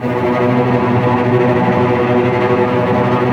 Index of /90_sSampleCDs/Roland L-CD702/VOL-1/STR_Vcs Tremolo/STR_Vcs Trem f